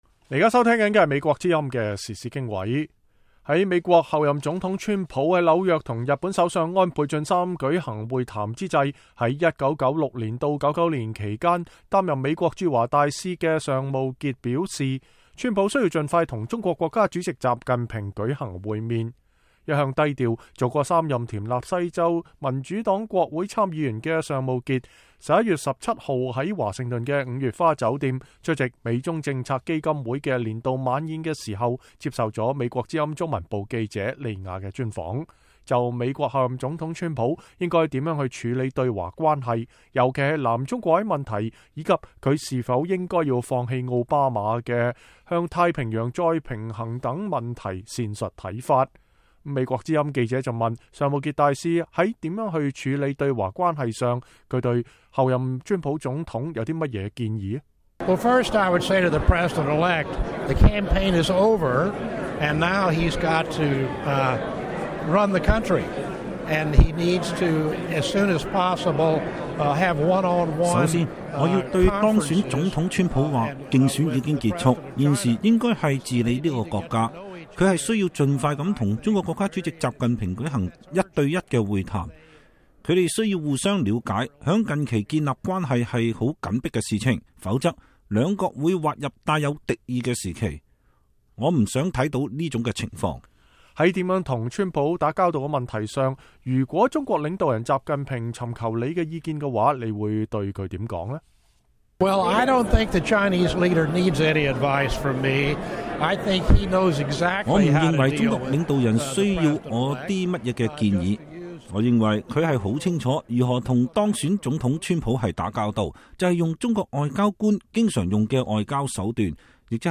VOA專訪尚慕杰：川普應盡快與習近平會晤